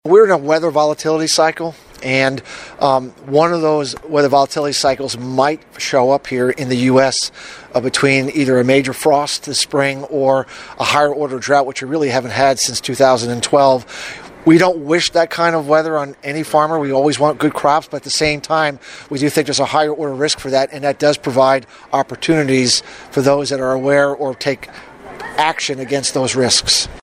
The 20th annual Kentucky Soybean Promotion Day at Murray State University Tuesday provided a reality check, a word of optimism for the future of agriculture, and a look at potential weather influences.